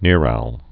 (nîrăl)